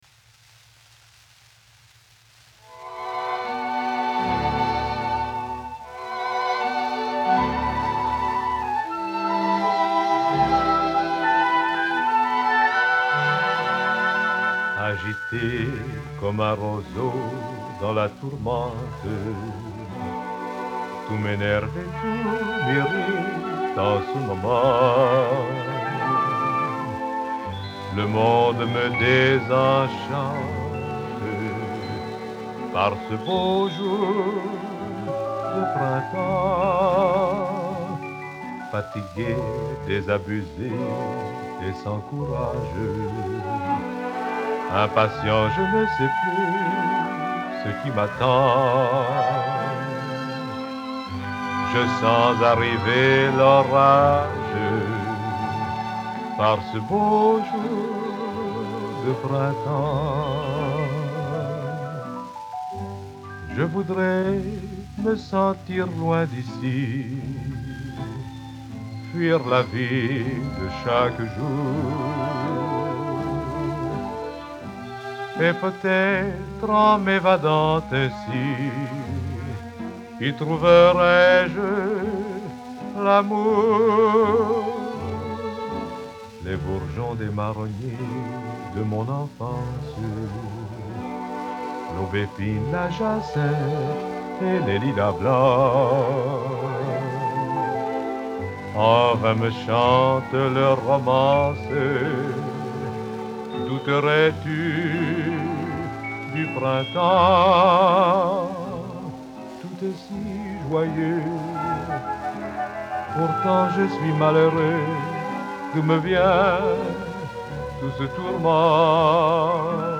очень романтический стиль.